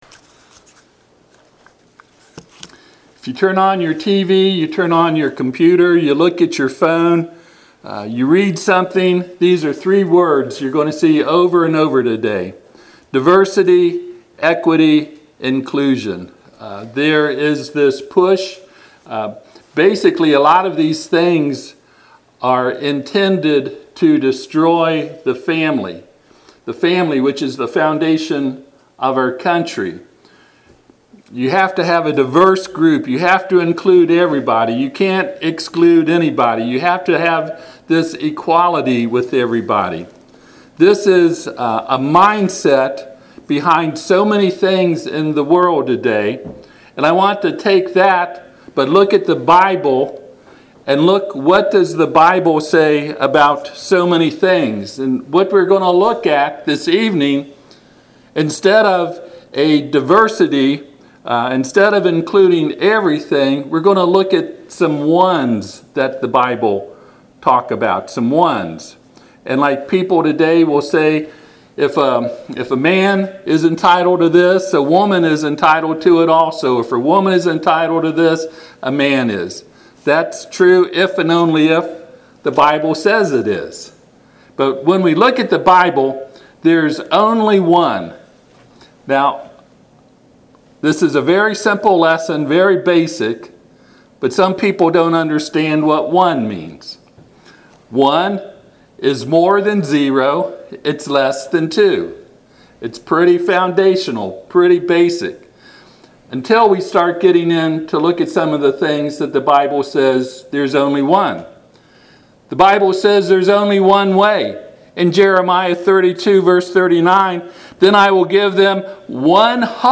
John 14:6 Service Type: Sunday PM https